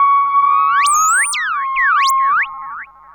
Theremin_FX_01.wav